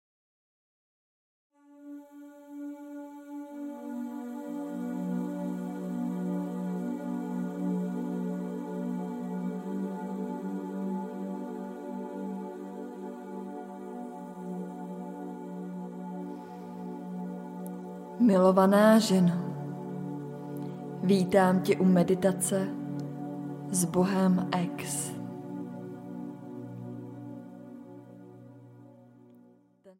Sbohem ex audiokniha
Ukázka z knihy